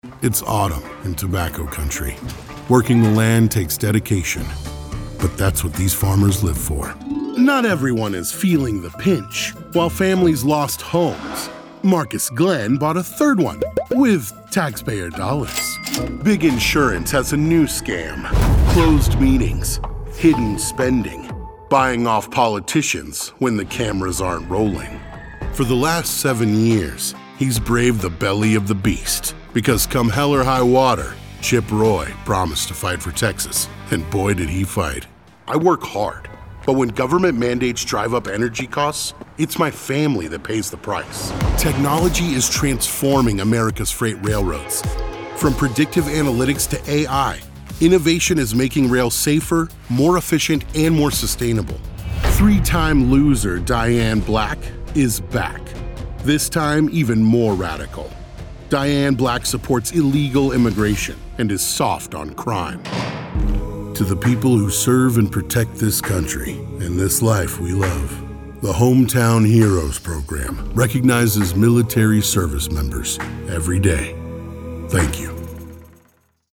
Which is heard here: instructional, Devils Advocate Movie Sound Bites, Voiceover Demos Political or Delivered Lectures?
Voiceover Demos Political